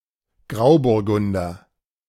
Pinot gris (French pronunciation: [pino ɡʁi] ), pinot grigio (US: /ˈpn ˈɡri, -/, UK: /ˈɡrɪi/), or Grauburgunder (German pronunciation: [ˈɡʁaʊbʊʁˌɡʊndɐ]
De-Grauburgunder.ogg.mp3